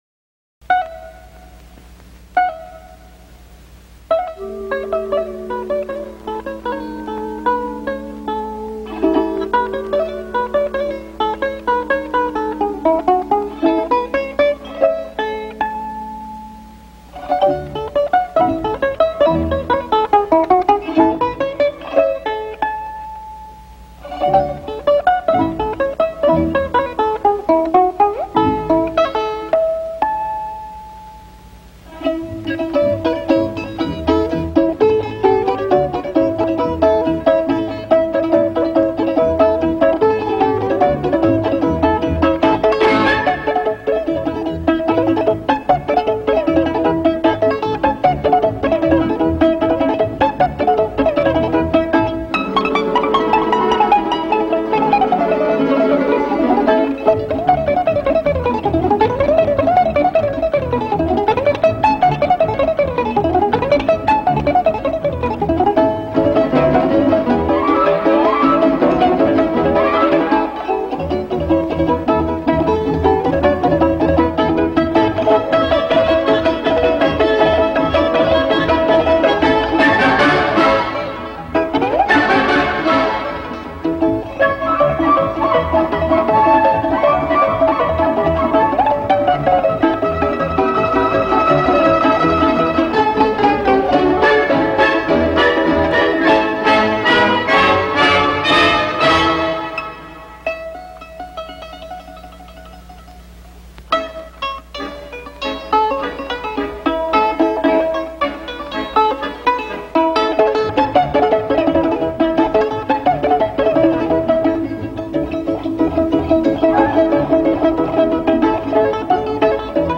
Balalaika
Balalaika-Spiel :